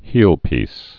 (hēlpēs)